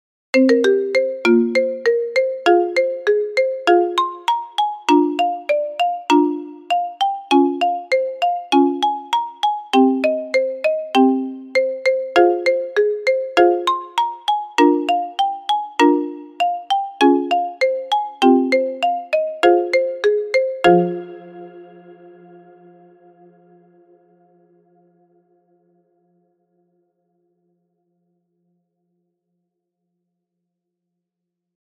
Marimba Remix